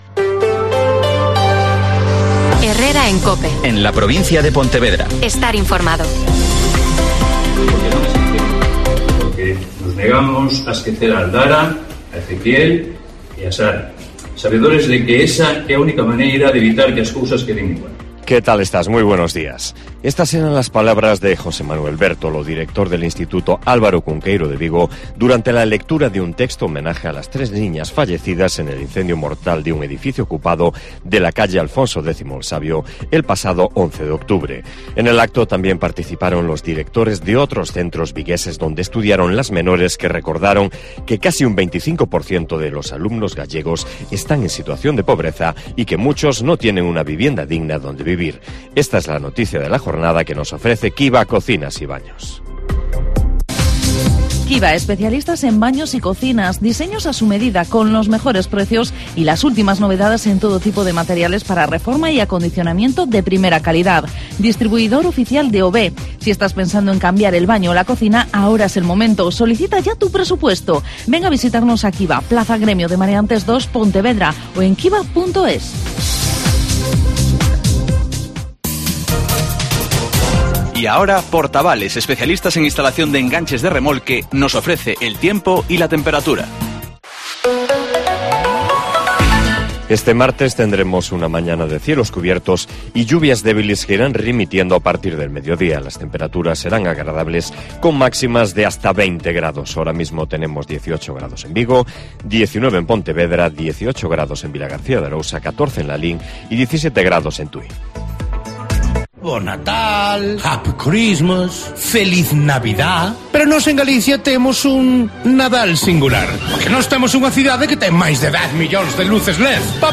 Herrera en COPE Pontevedra y COPE Ría de Arosa (Informativo 08:24h)